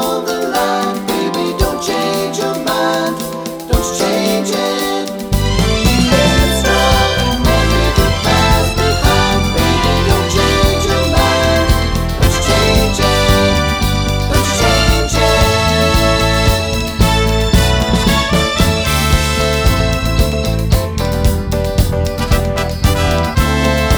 No Backing Vocals Soul / Motown 3:19 Buy £1.50